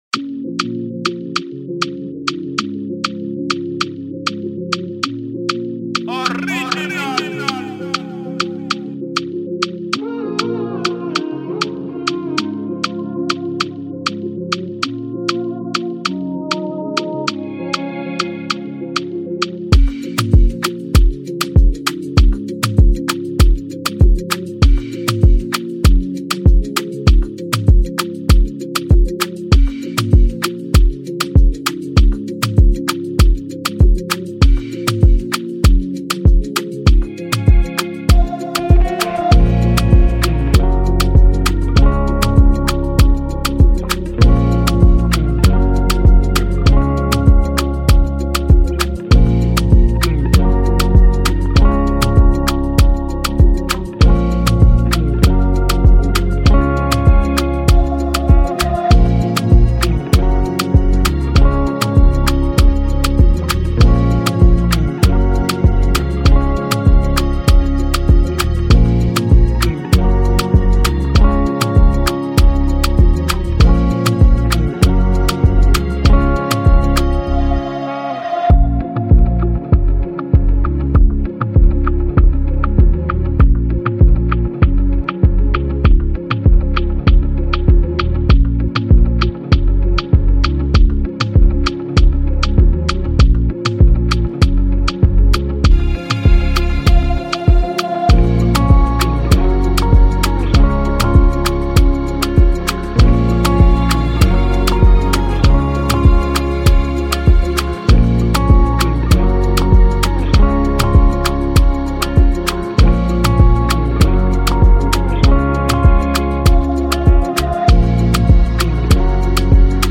official instrumental
2024 in Dancehall/Afrobeats Instrumentals